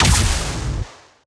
Added more sound effects.
GUNArtl_Rocket Launcher Fire_06_SFRMS_SCIWPNS.wav